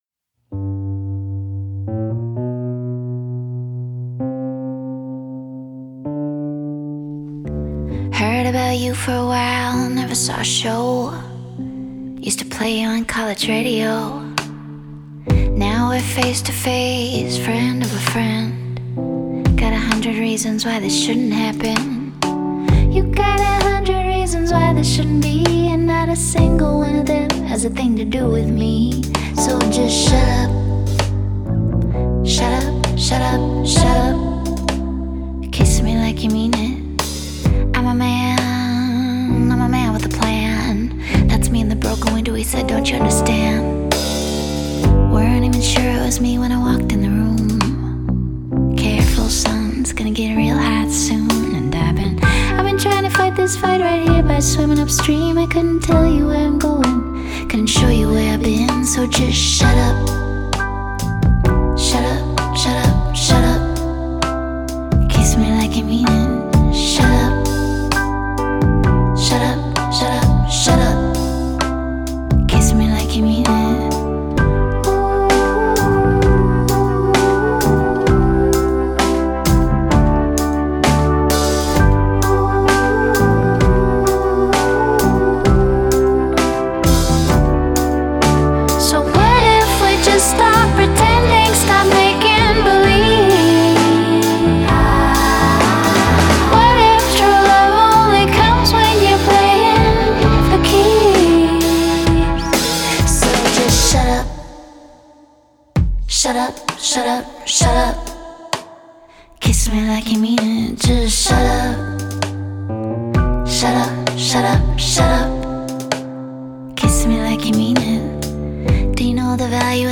Genre: Singer/Songwriter, Indie Pop, Rock, Folk